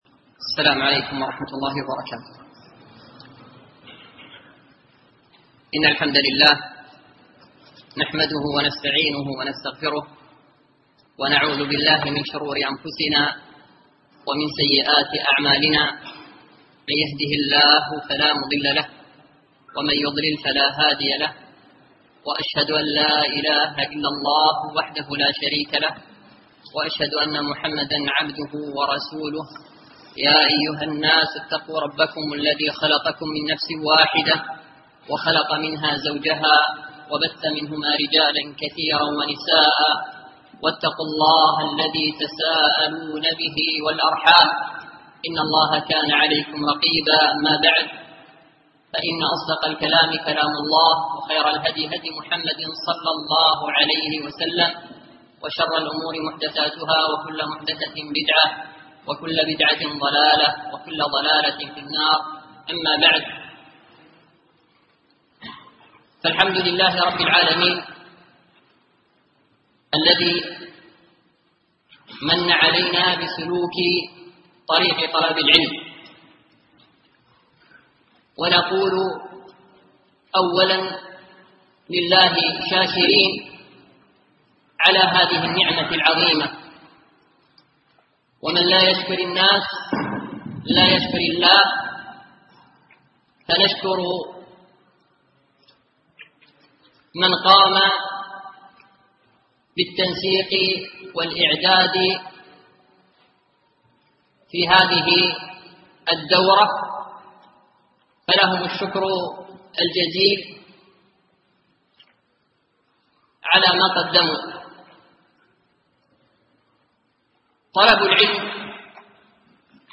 شرح منظومة الأسس المفيدة من منهاج أهل الإحسان في العقيدة ـ الدرس الأول